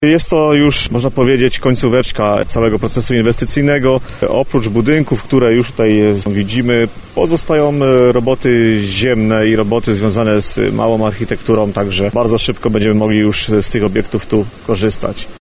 Obecnie trwają prace przy budowie tężni solankowej, pijalni wód mineralnych, auli do muzykoterapii i ścieżkach ruchowych. Inwestycje te mają być gotowe już w sierpniu – zapewnia Marek Mikrut wicewójt gm. Dębica.